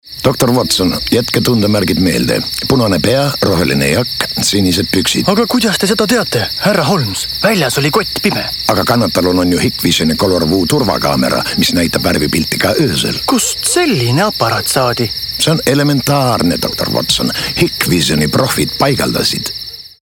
Raadioreklaamid